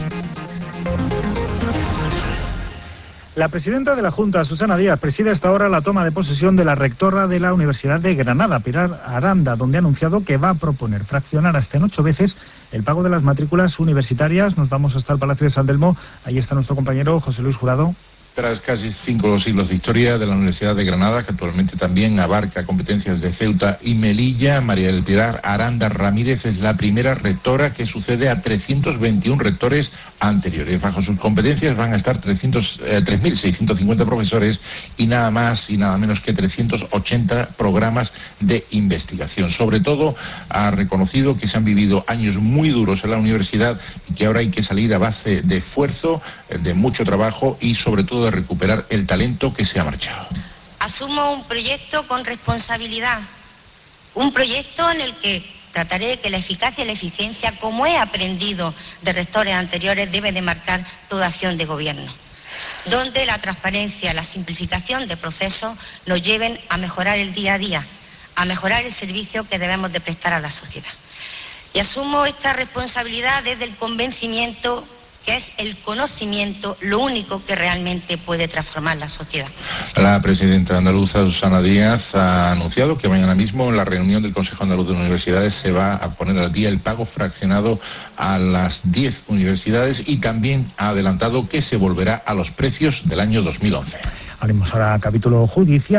La Presidenta de la Junta de Andalucía, Susana Díaz, que ha presidido hoy la toma de posesión de la nueva rectora de la Universidad de Granada, Pilar Aranda, ha resaltado que mañana cumplirá con el compromiso del pago fraccionado en ocho mensualidades de las matrículas en Andalucía y ha asegurado que se va a volver a los precios de 2011. Decl. Pilar Aranda, Rectora de la Universidad de Granada.